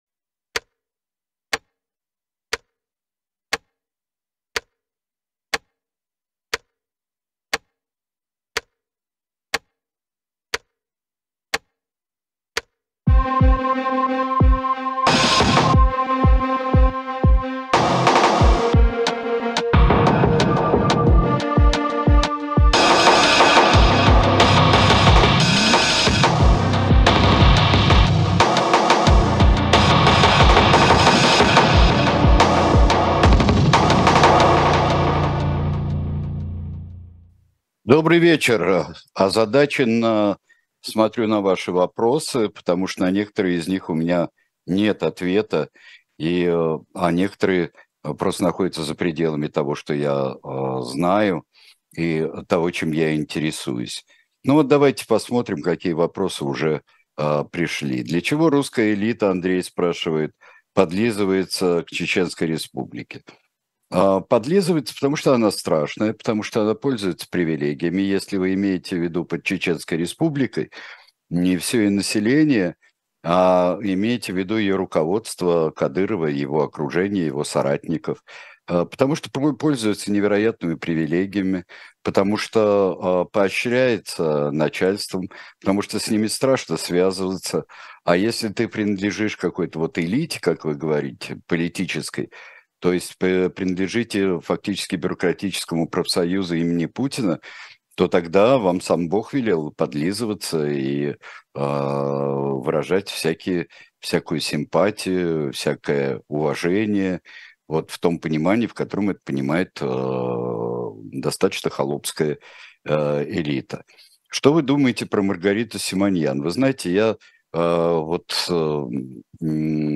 На ваши вопросы в прямом эфире отвечает Сергей Бунтман.